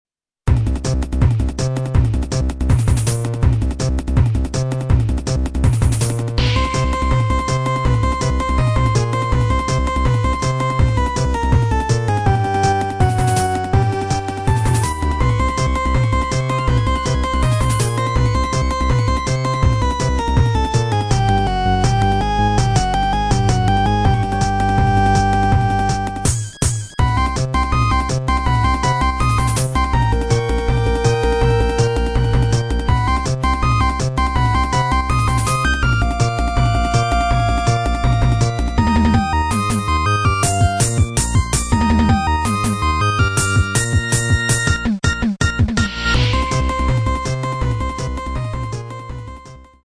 （音質　16kbps〜48kbps　モノラル）